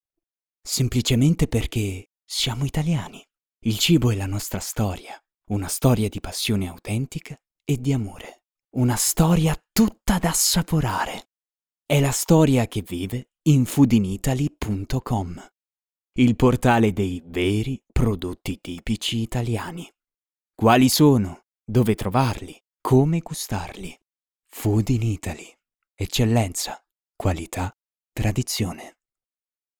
Dubber, speaker, italian voiceover, commercials, corporate, E-learning, animations, jingle, singer, games, youtube videos, documentaries,
Sprechprobe: Industrie (Muttersprache):
emozionale_0.mp3